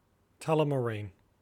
Tullamarine (/ˌtʌləməˈrn/
En-au-tullamarine.oga.mp3